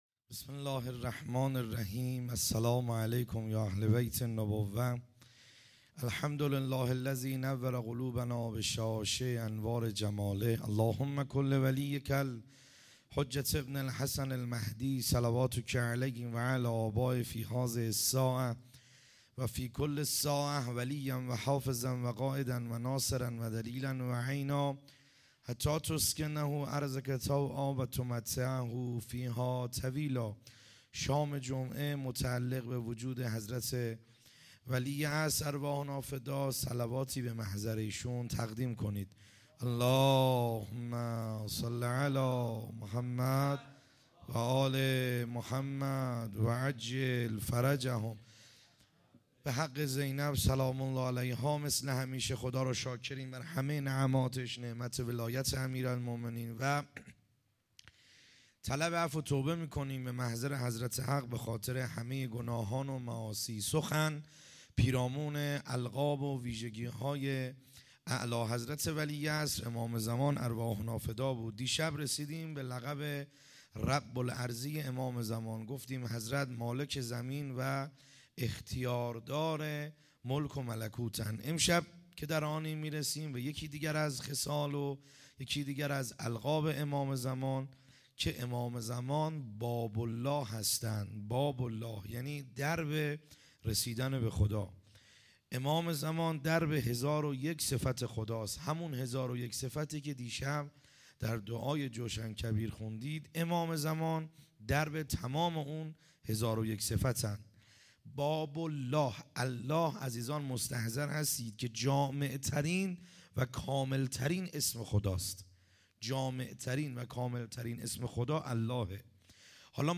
خیمه گاه - بیرق معظم محبین حضرت صاحب الزمان(عج) - سخنرانی ا شب دوازدهم